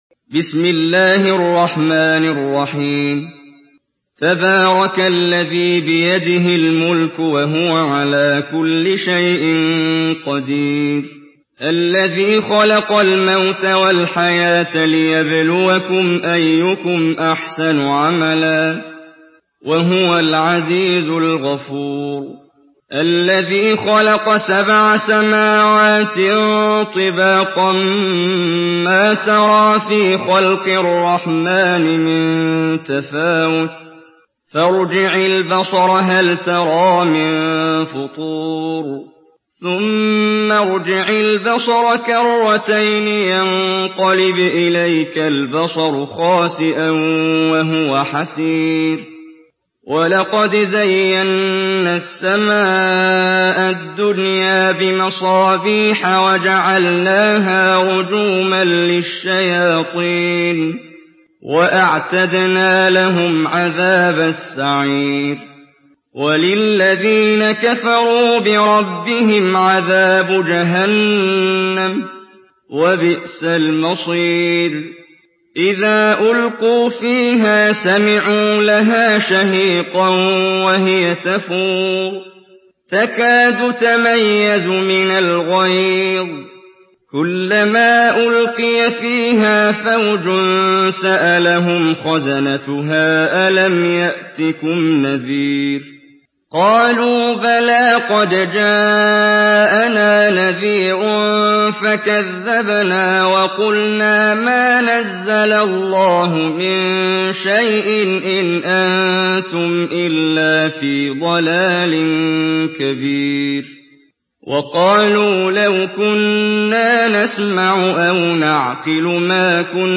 Surah Mülk MP3 in the Voice of Muhammad Jibreel in Hafs Narration
Murattal